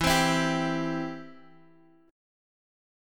Em#5 chord